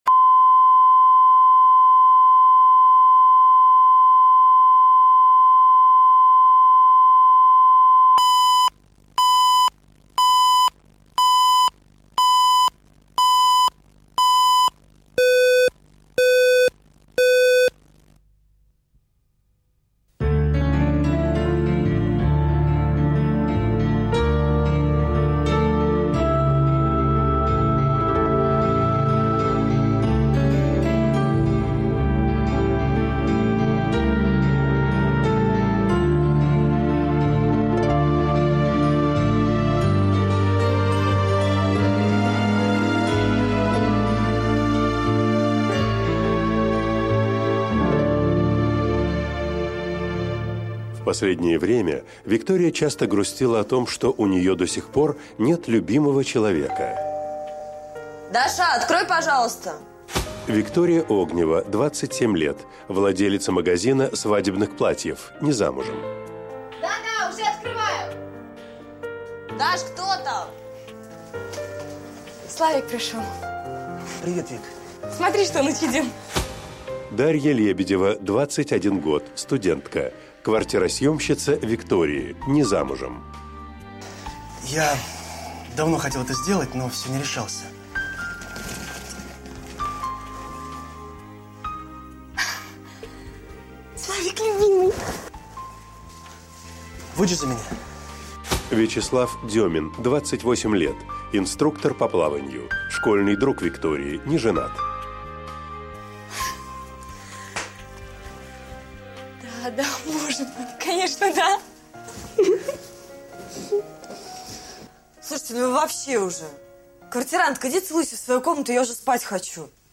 Аудиокнига Смотри на дождь | Библиотека аудиокниг
Прослушать и бесплатно скачать фрагмент аудиокниги